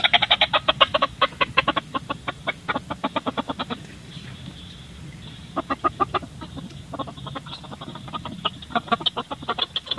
Звук вороны, которая каркает «каррр-каррр»